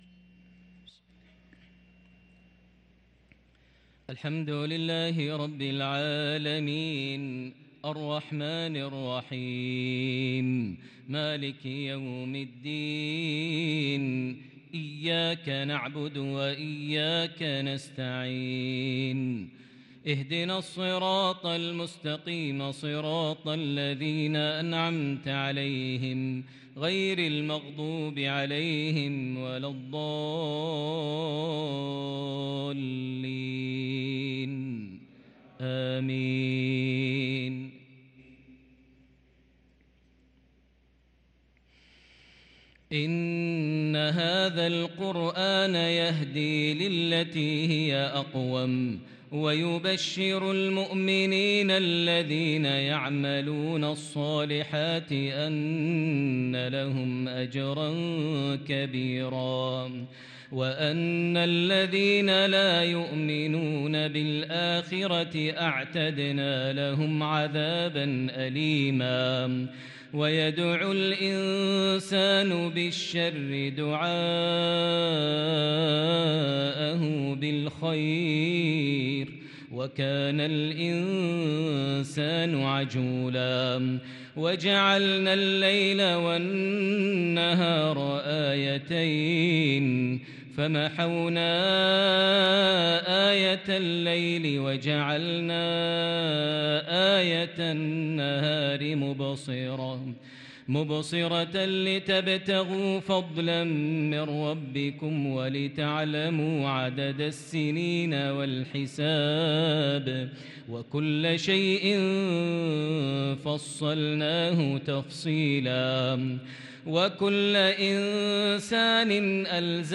صلاة العشاء للقارئ ماهر المعيقلي 14 ربيع الآخر 1444 هـ
تِلَاوَات الْحَرَمَيْن .